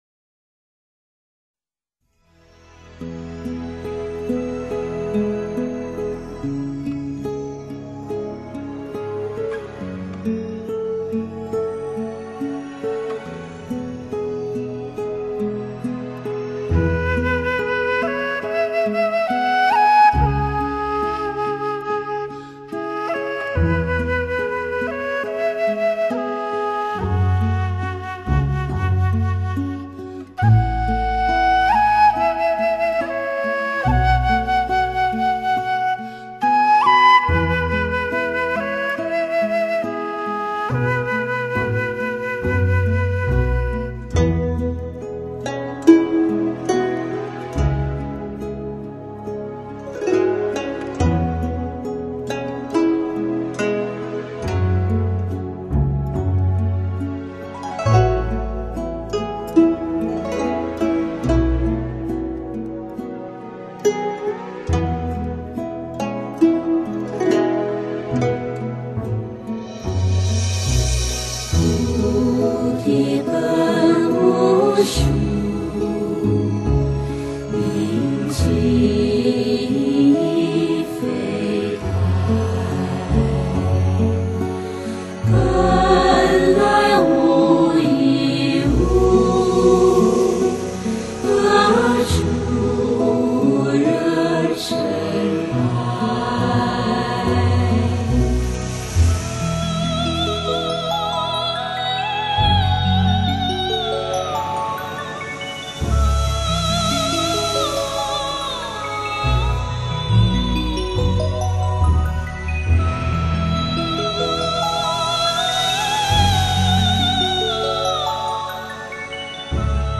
由多种乐器轮流演奏的主部，旋律抒情柔美。“